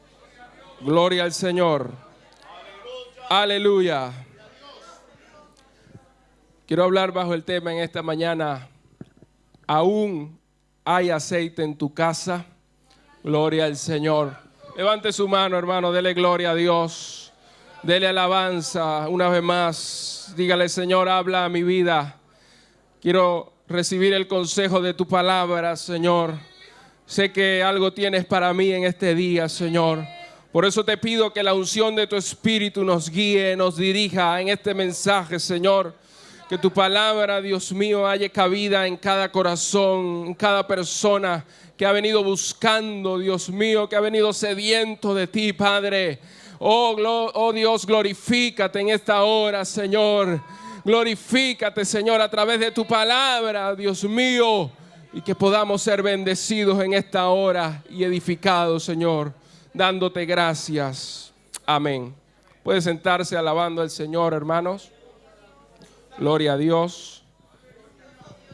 Sermó religiós